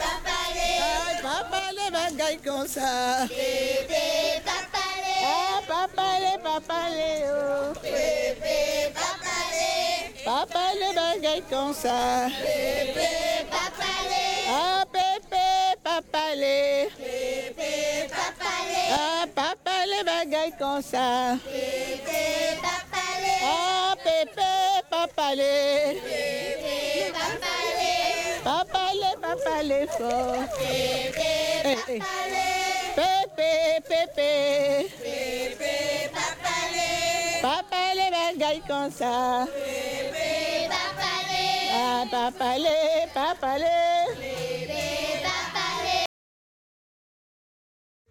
Marche dans les rues.
Pièce musicale inédite